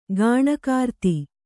♪ gāṇakārti